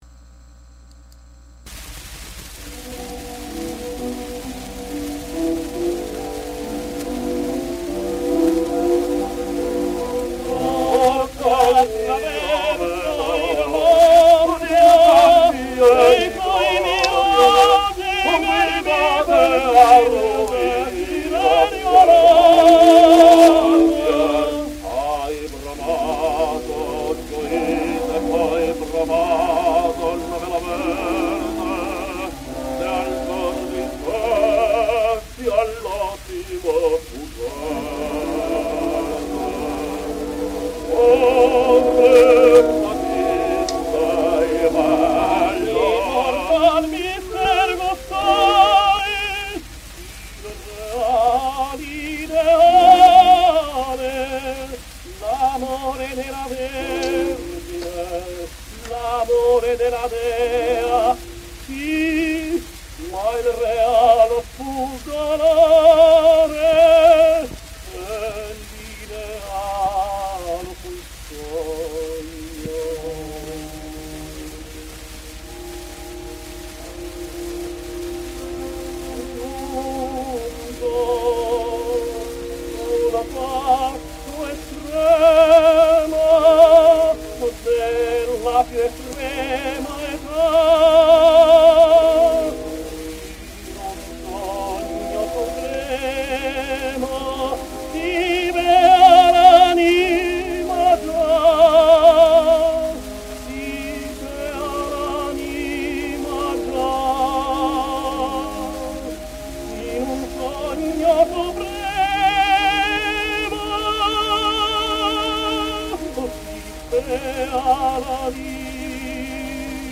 Luca Botta sings Mefistofele: